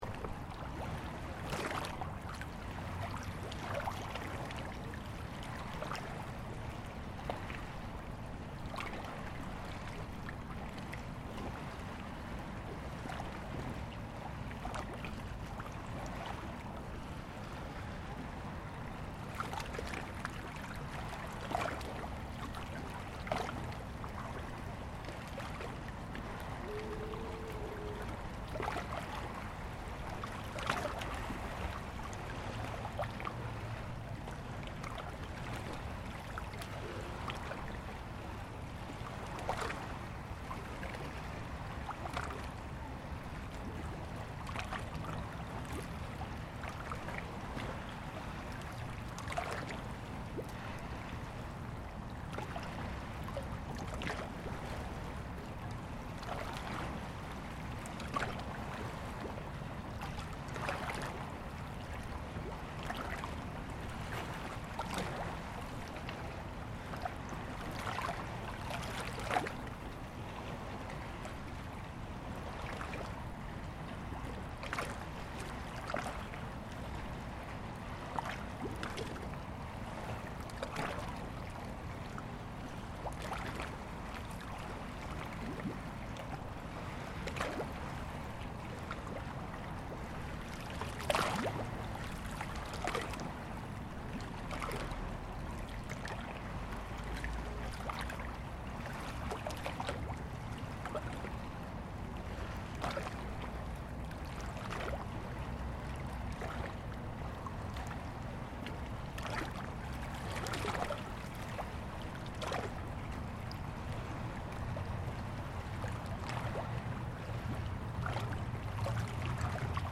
At this time of January the Neva river in St. Petersburg is usually frozen. The 2020 winter was extremely warm. The sound was recorded on the bank of the Peter and Paul Fortress. You can hear the waves and some sounds from under the stone arch wave echoes.